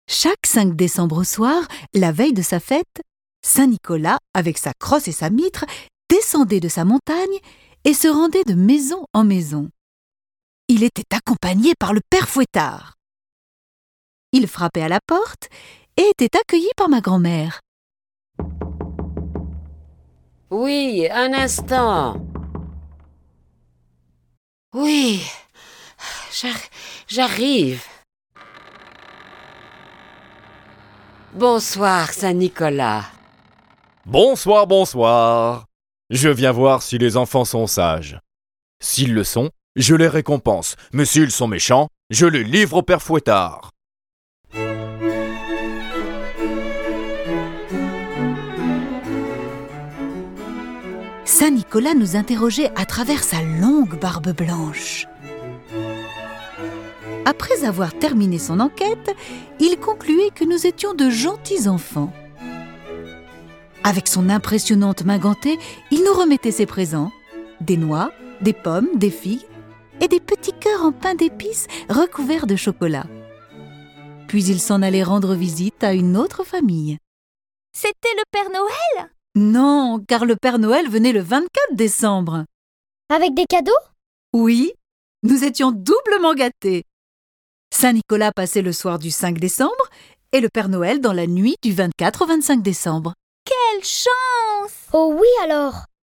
Cette version sonore de la vie de Nicolas est animée par dix voix et accompagnée de plus de trente morceaux de musique classique.